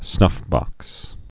(snŭfbŏks)